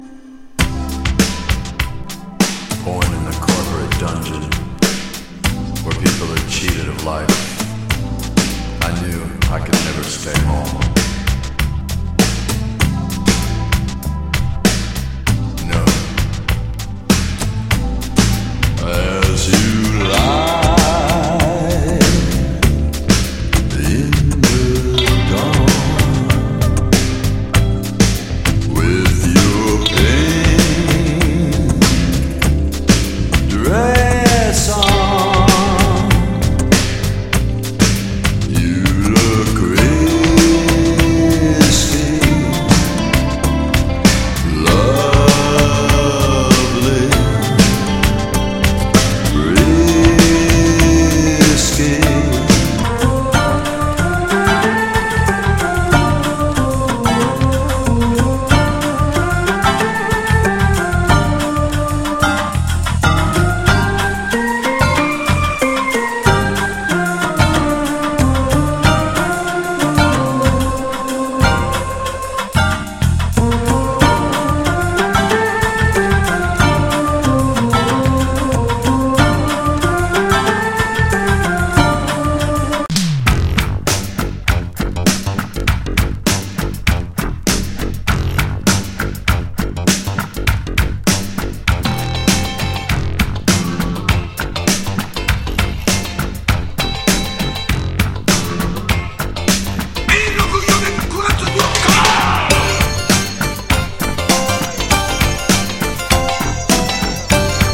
和レアリック
沖縄エレクトロニクス
ストレンジ・ファンク